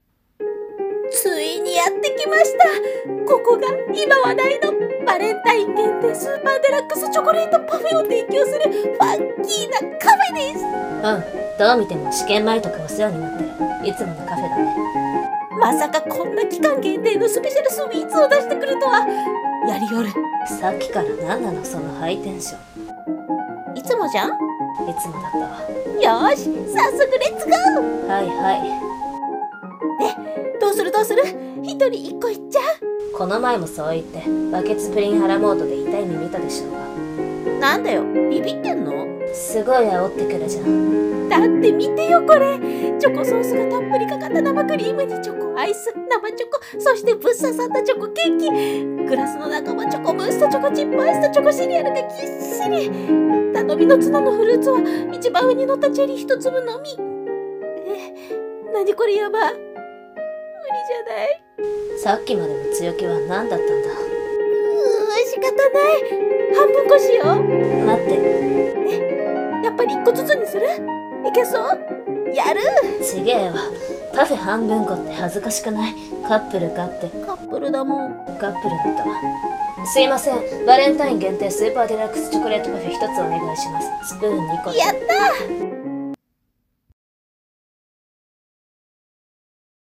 【二人声劇】チョコレートパフェを一緒に